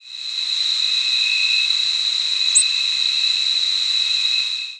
Ovenbird nocturnal
presumed Ovenbird nocturnal flight calls